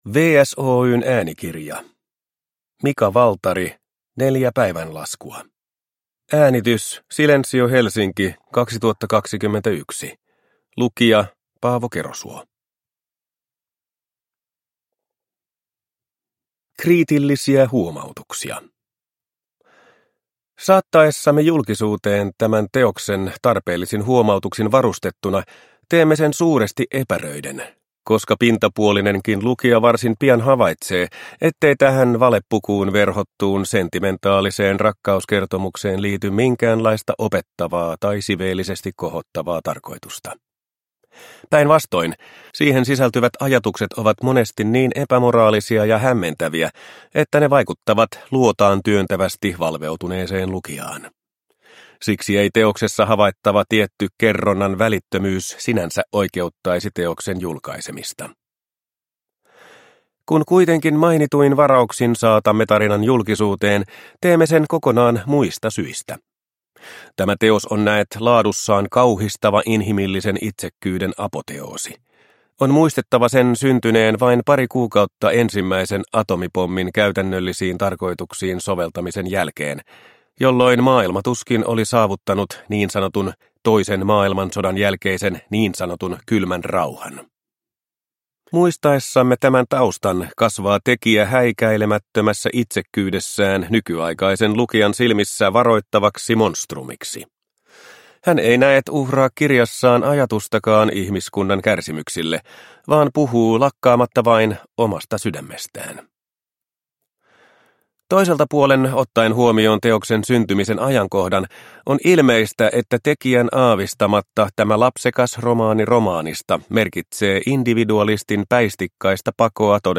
Neljä päivänlaskua – Ljudbok – Laddas ner